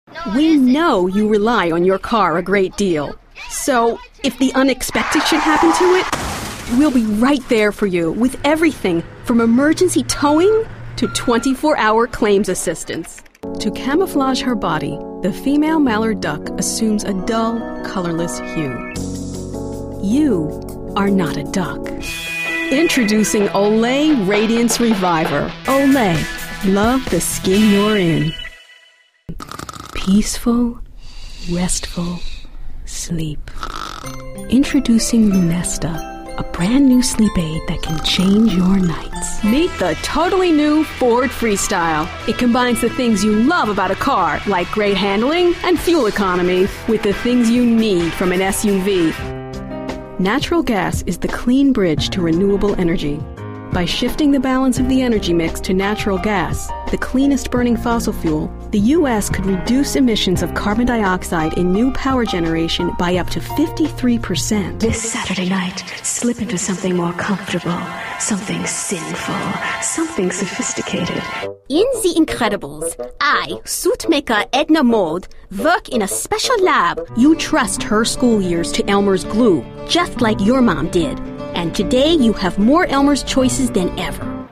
Real enough to sound natural yet trained enough to sound professional.
Versatile, friendly and elegant.
Sprechprobe: Sonstiges (Muttersprache):
My work always features a neutral accent, clear pronunciation and natural expression.